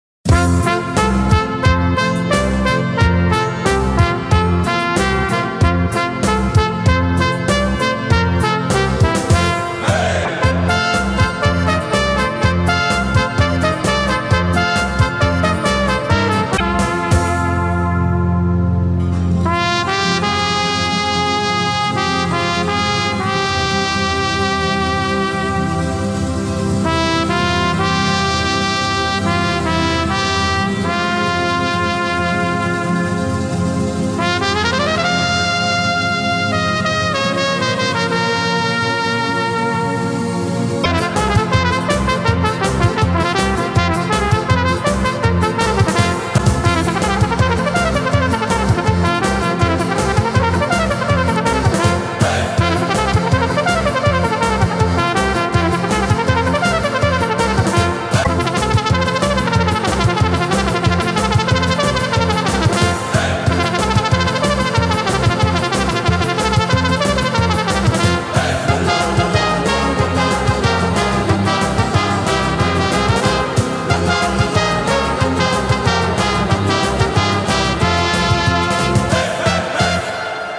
Gattung: Solo für Trompete und Blasorchester
Besetzung: Blasorchester
eine geniale Show-Nummer
für Solo-Trompete und Blasorchester